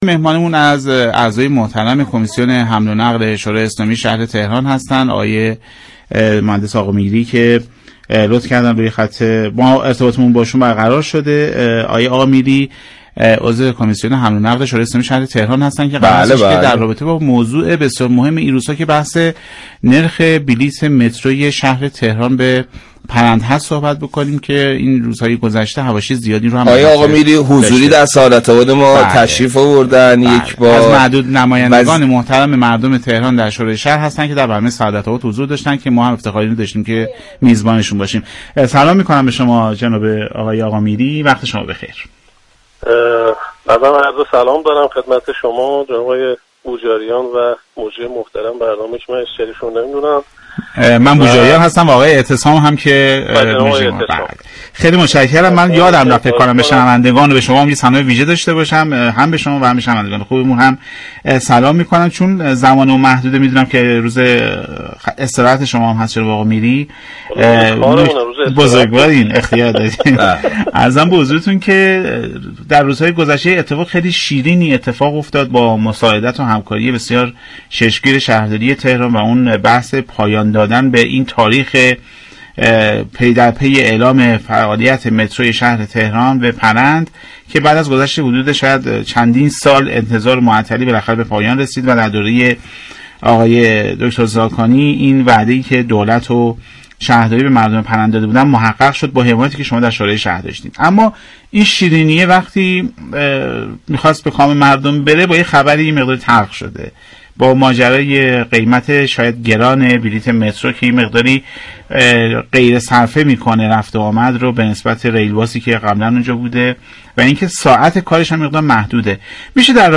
مردم یك سوم قیمت واقعی بلیت مترو تهران – پرند را پرداخت می‌كنند به گزارش پایگاه اطلاع رسانی رادیو تهران، محمد آقامیری عضو شورای اسلامی شهر تهران در گفت و گو با «سعادت آباد» درخصوص مبنای نرخ گذاری مترو اظهار داشت: نرخ‌ بلیت مترو تهران - پرند برای مسافران معادل یك سوم قیمت تمام شده است؛ یك سوم مبلغ را شهرداری و یك سوم دیگر را دولت پرداخت می‌كند.